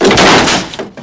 crateBreak3.ogg